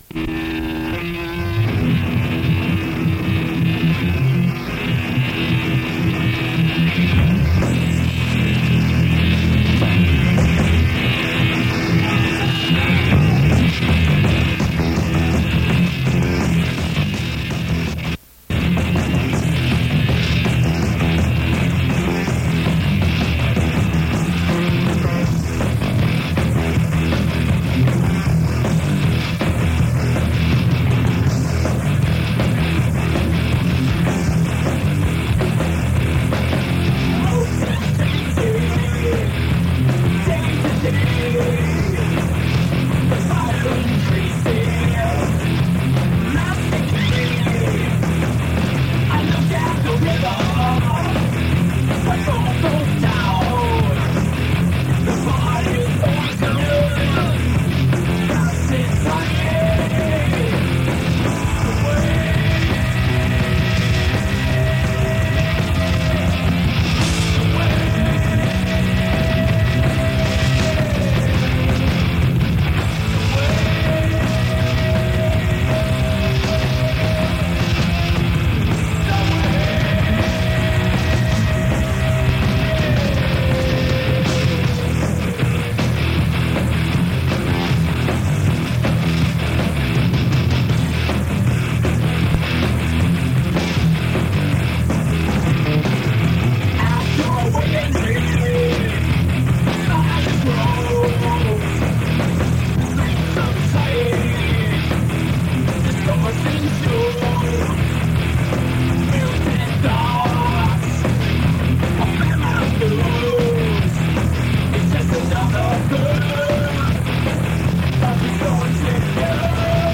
En ese entonces vivía en Villaflores (Chiapas) y logré captar estaciones de radio de la ciudad de Tuxtla Gutiérrez, las cuales grabé en mi grabadora de casete.
cassete-programa-radio-rock-96.mp3